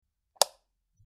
Light Switch Click 03
Light_switch_click_03.mp3